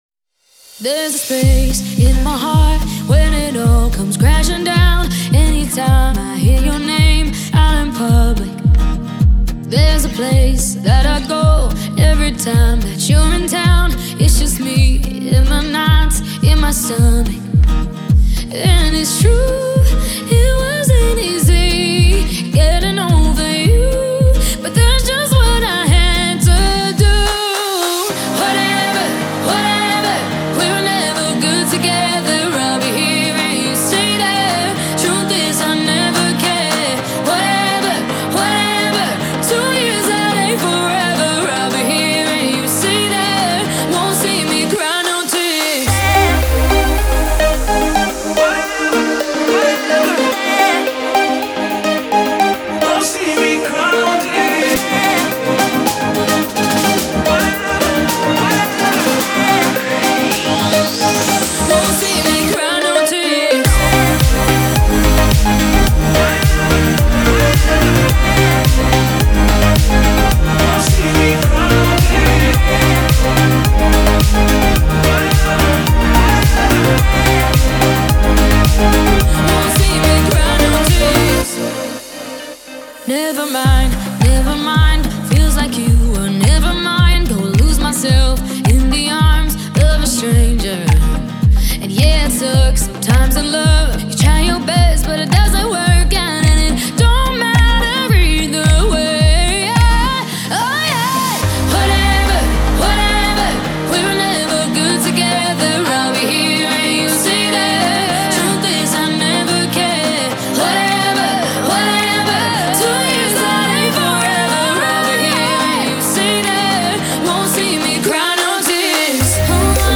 с этим диджеем- точно получится более подвижно